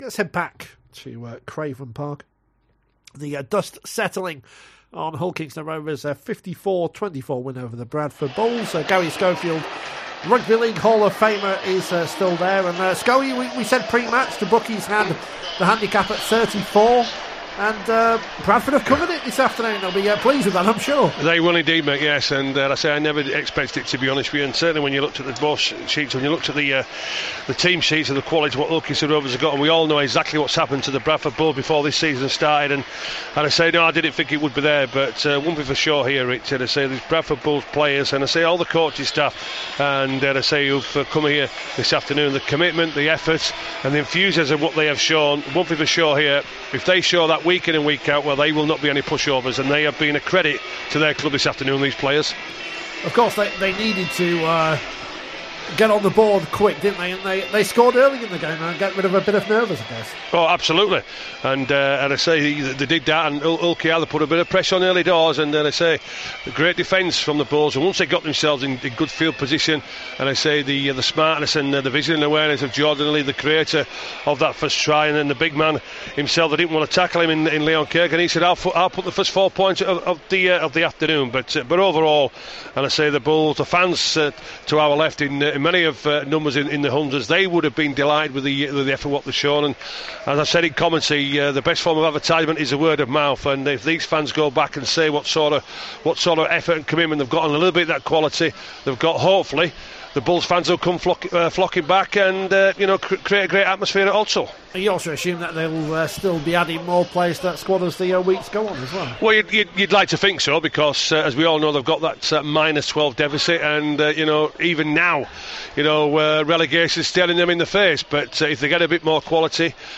join us in the studio to look ahead to the Super League season